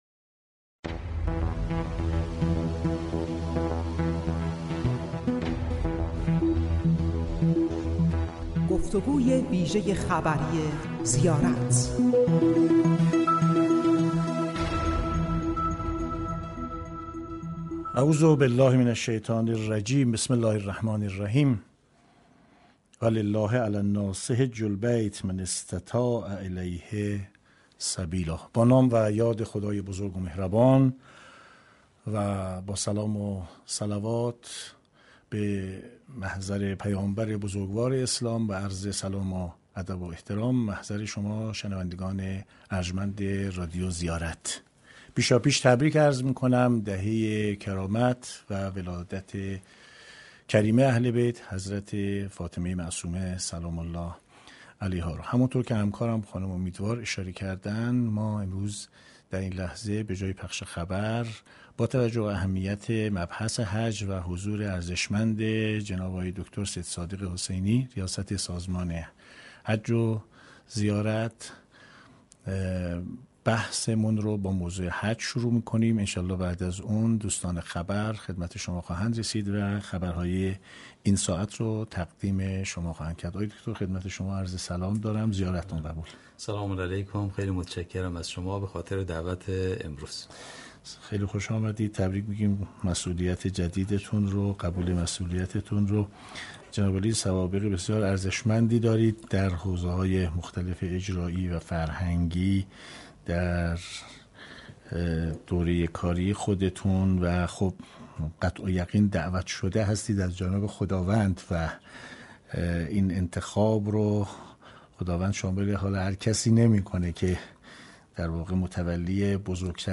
به گزارش رادیو زیارت، سید صادق حسینی در گفتگوی ویژه خبری این رادیو افزود: هر زائر 28 روز در سرزمین وحی به سر می برد.